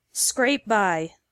• Listen to the pronunciation